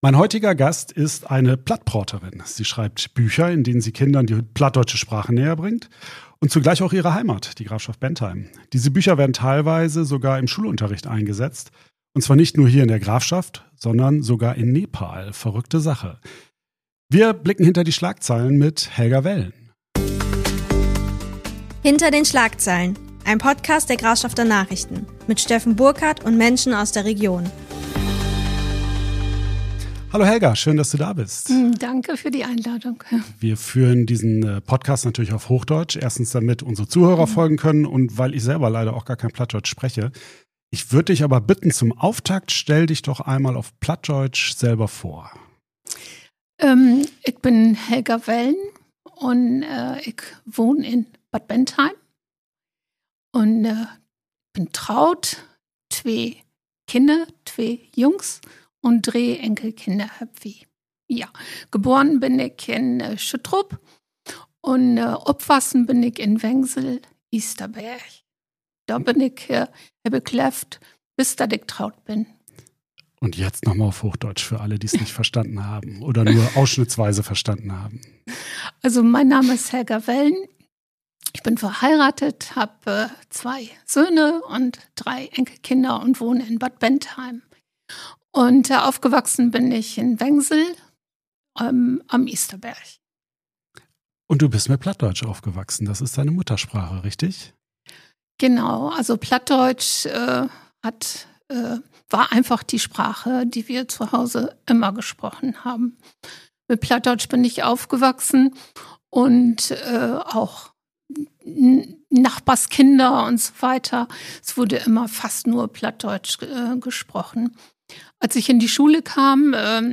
Ein Gespräch über Sprache, Kultur, Identität und die Kraft von Geschichten – von der Grafschaft hinaus in die Welt.